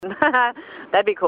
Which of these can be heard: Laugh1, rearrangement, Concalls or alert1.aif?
Laugh1